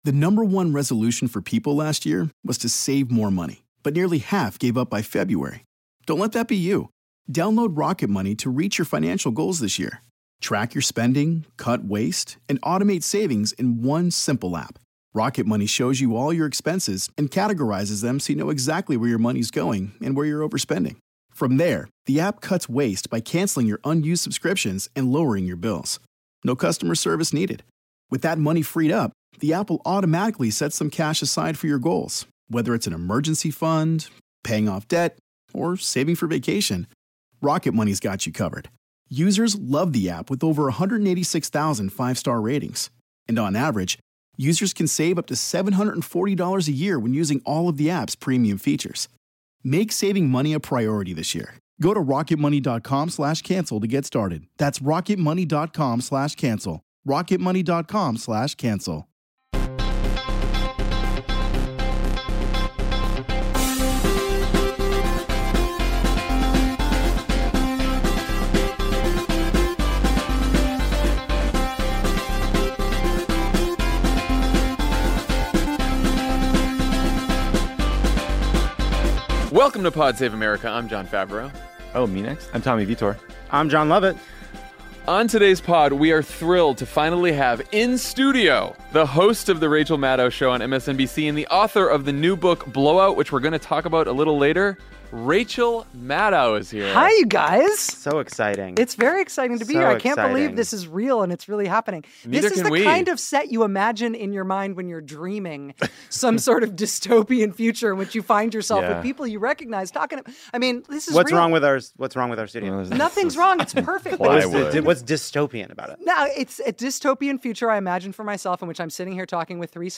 Rachel Maddow joins as guest co-host to talk about the White House announcement that they intend to obstruct the impeachment inquiry, Trump’s decision to abandon our Kurdish allies, and Facebook’s refusal to pull down false ads about Joe Biden. Then we talk to Rachel about her new book, Blowout: Corrupted Democracy, Rogue State Russia, and the Richest, Most Destructive Industry on Earth.